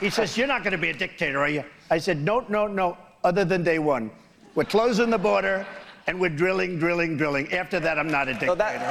At a townhall event aired on the FOX News Channel and moderated by Sean Hannity he had little to say about his GOP rivals.